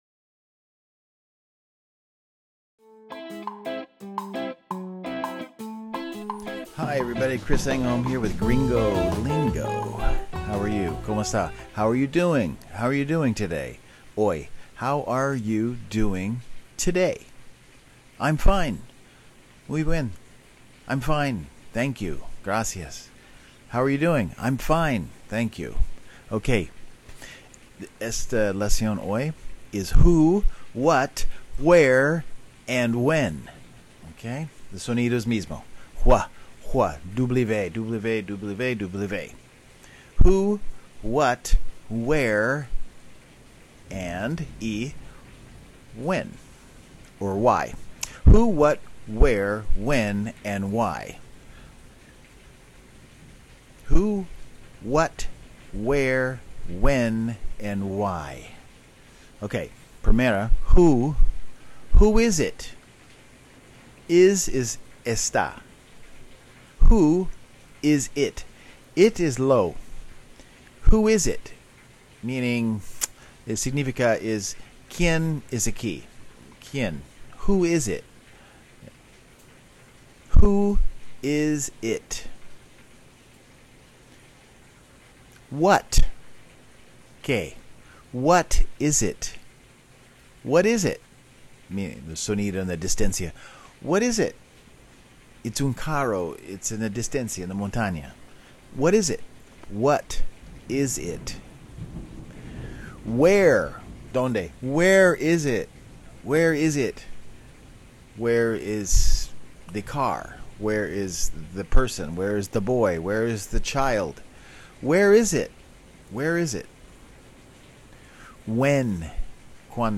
Short, practical English lessons designed for real-life use. Each episode breaks down a common expression, pronunciation challenge, or everyday phrase in a clear and approachable way, helping viewers sound more natural and confident in daily conversations.